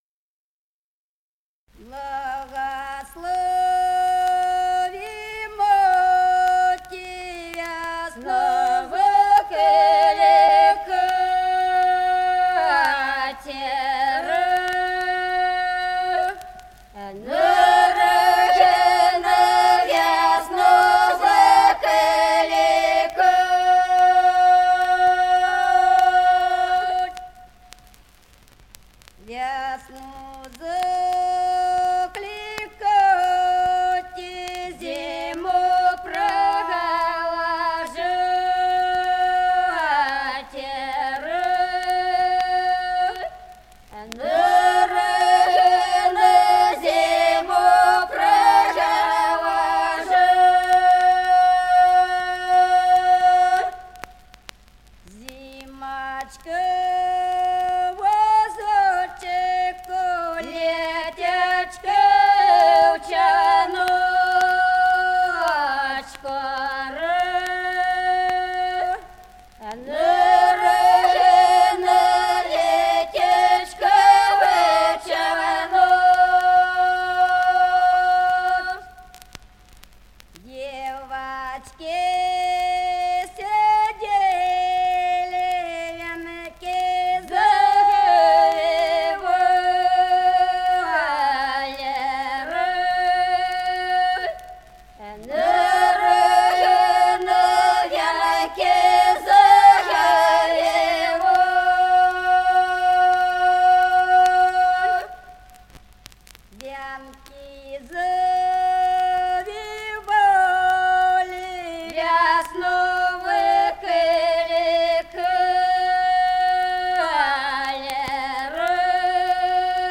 Песни села Остроглядово. Благослови, мати.